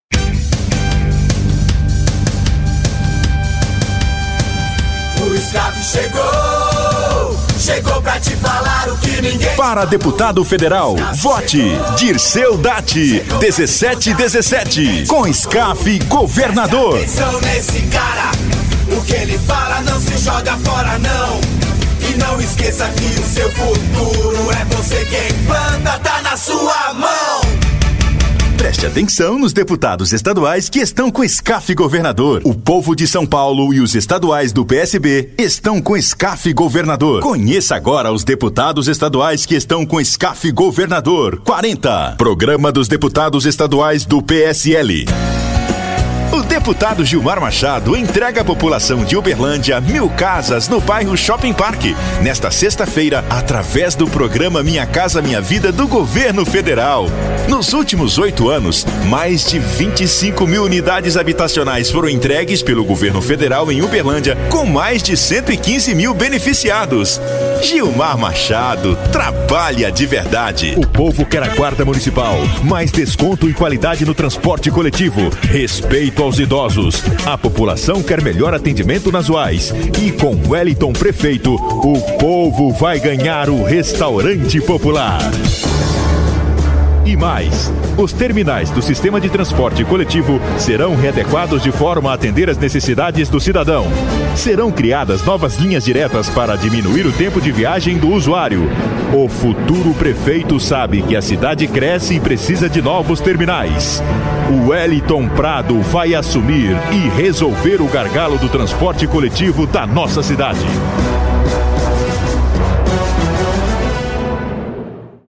DMVoz Produções - Locução profissional